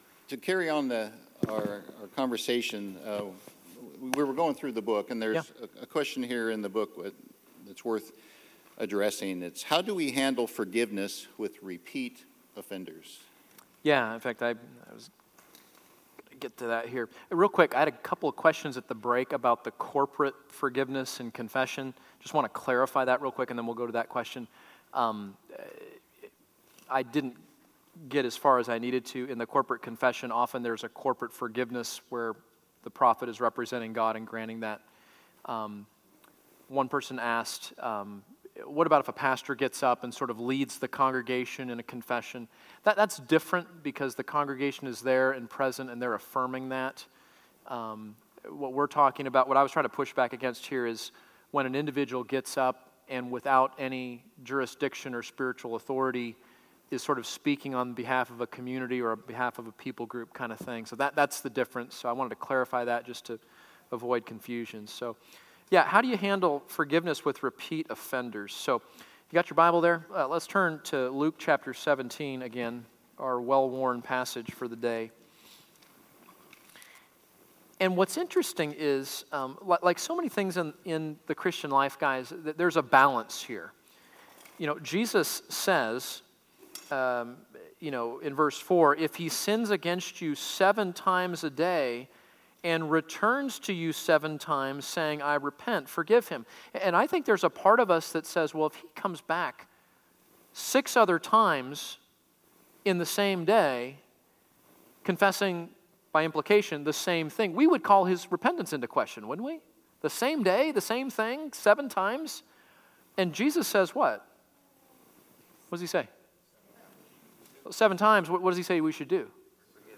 Pastoral Panel Discussion on Forgiveness